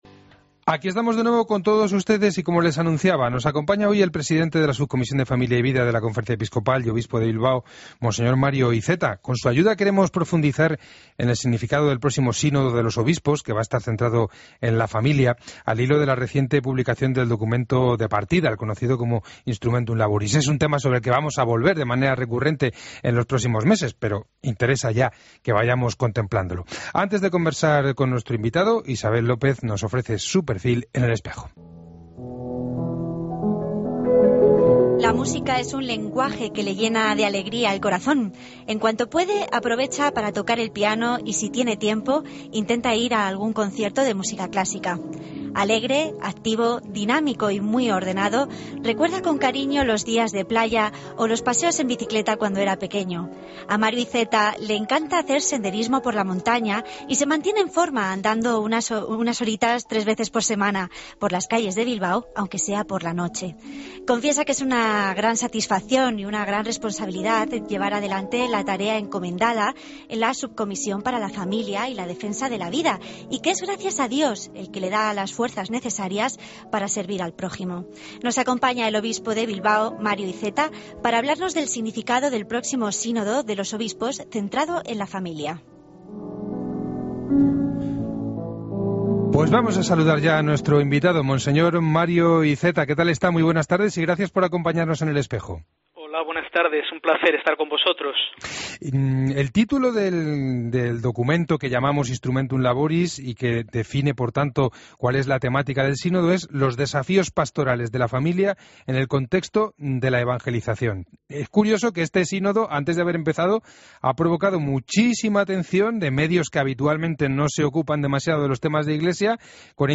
AUDIO: Escucha la entrevista completa al obispo de Bilbao en 'El Espejo'